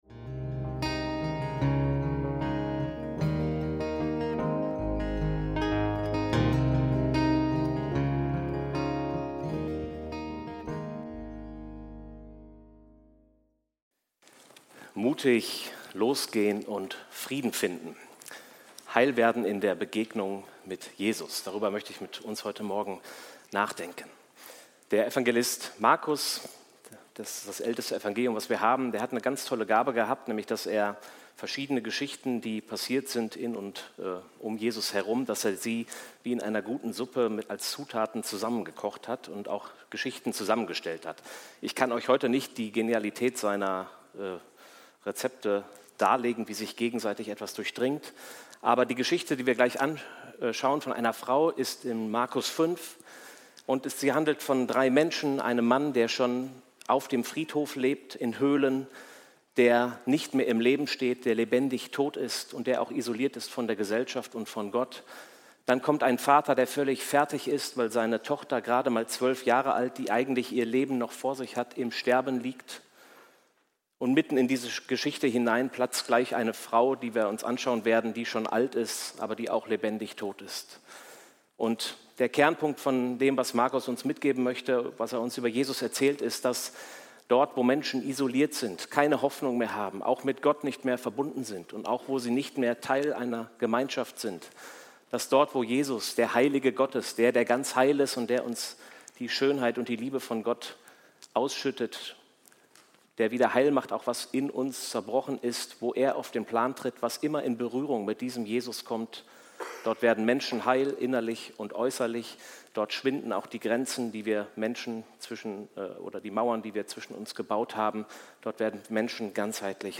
Mutig losgehen und Frieden finden - Predigt vom 14.09.2025